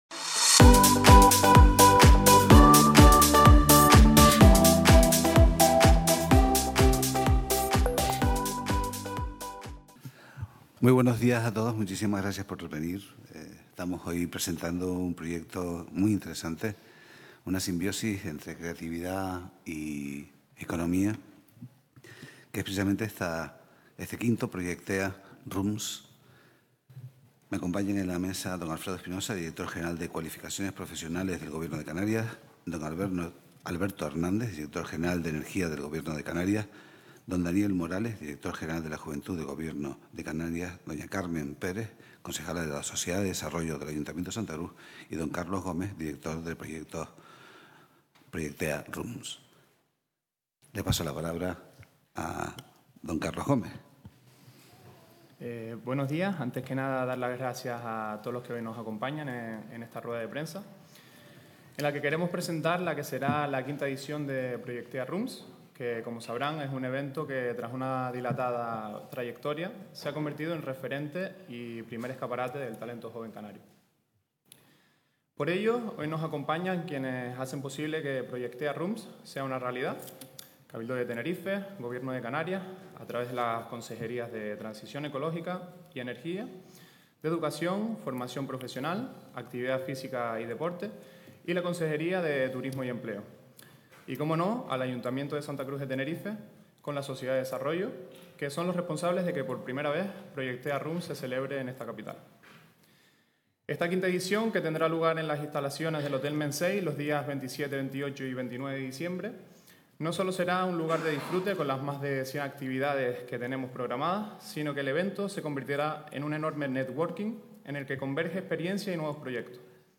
Canal Tenerife TV | El Cabildo acoge la presentación de la quinta edición de Proyectea Rooms